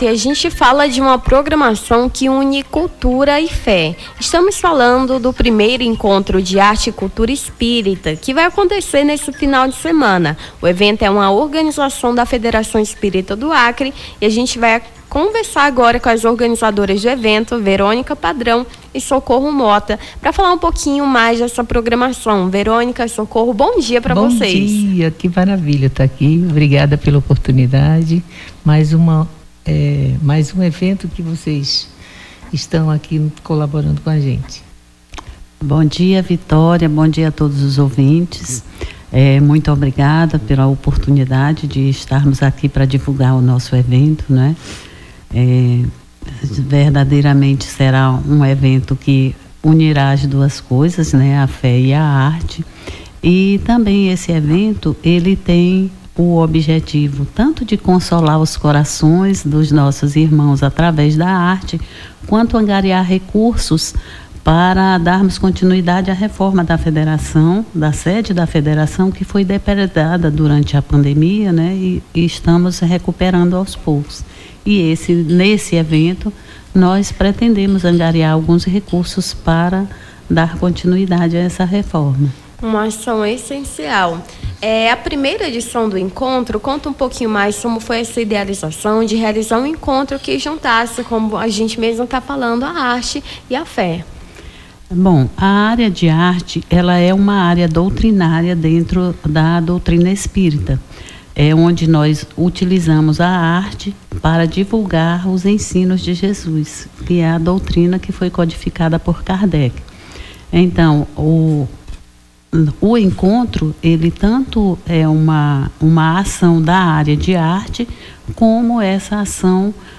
Baixar Esta Trilha Nome do Artista - CENSURA - ENTREVISTA ENCONTRO DE ARTE E CULTURA ESPÍRITA (27-03-25).mp3 Foto: Arquivo Pessoal Facebook Twitter LinkedIn Whatsapp Whatsapp Tópicos Rio Branco Acre Espírita Religião encontro Arte e Cultura